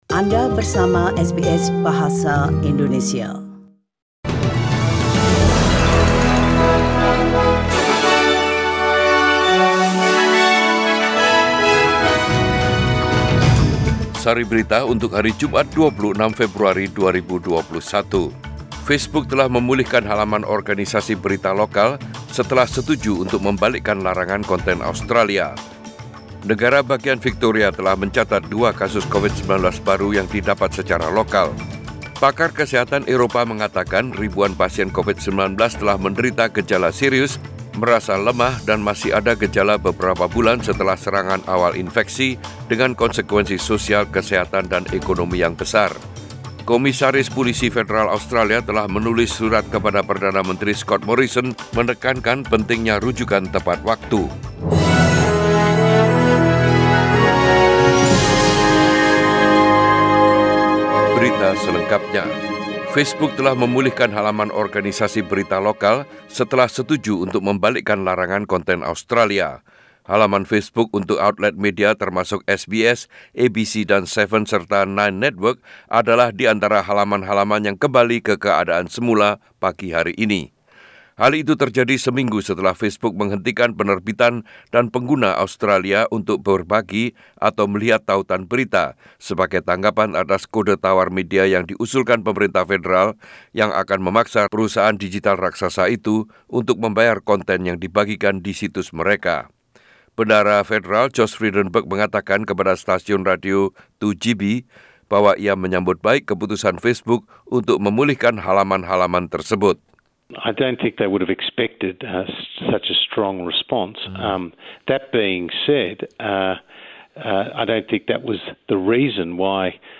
SBS Radio News in Bahasa Indonesia - 26 Feb 2021